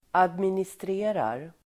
Uttal: [administr'e:rar]